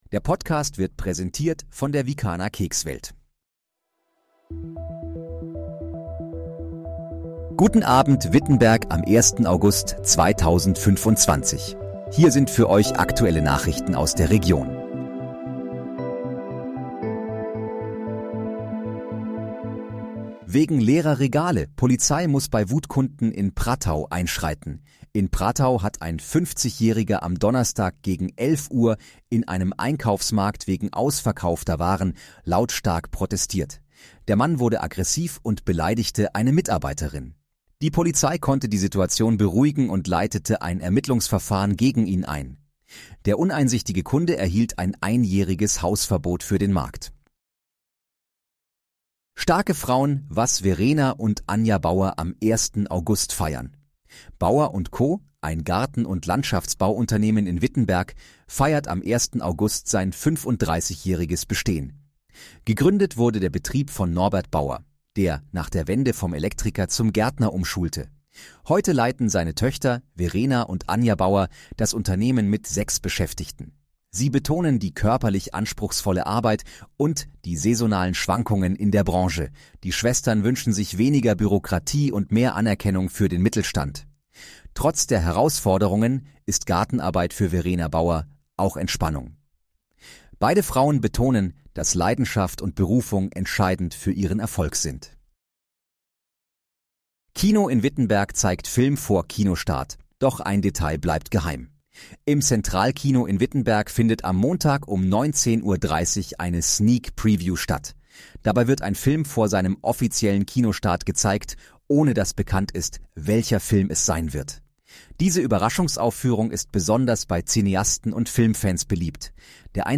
Guten Abend, Wittenberg: Aktuelle Nachrichten vom 01.08.2025, erstellt mit KI-Unterstützung
Nachrichten